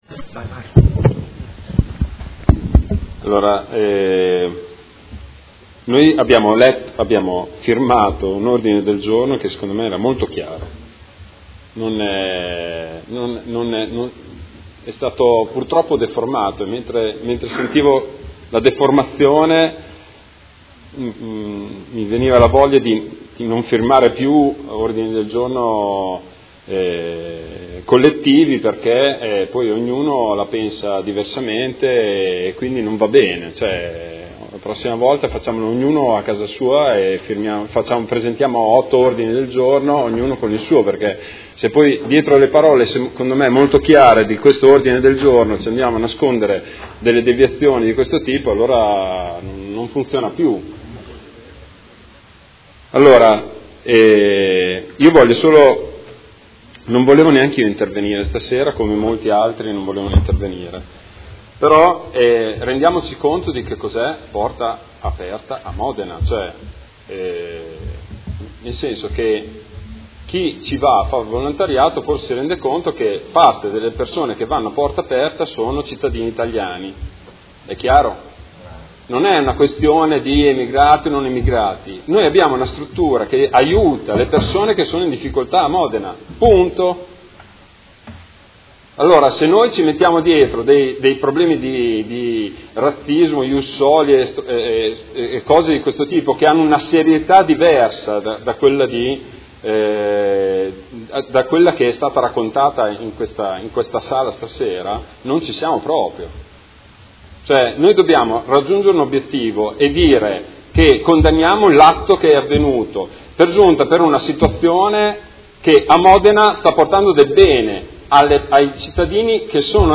Seduta del 20/07/2017 Odg 112553: Condanna atto vandalico a Porta Aperta